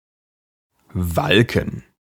Ääntäminen
IPA : /biːt/